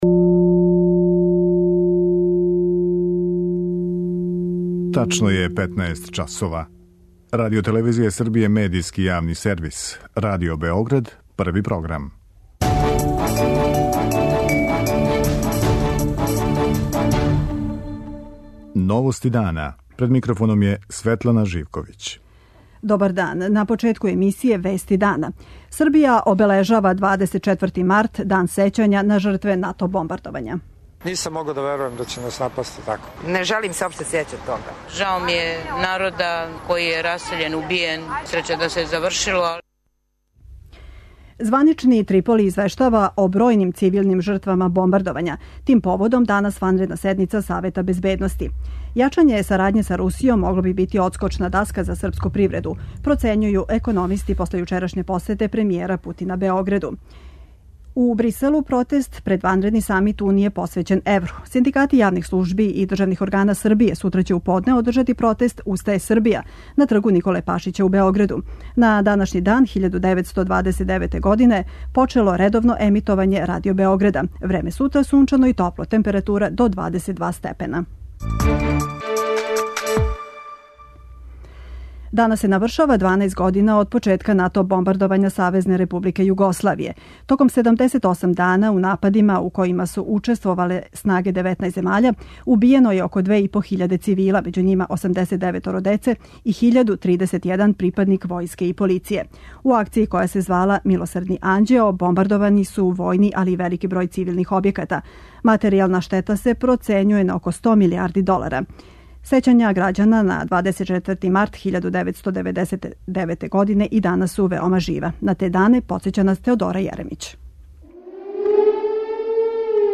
У Новостима дана чућемо како је звучао први радио програм на овим просторима.